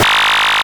Glitch FX 31.wav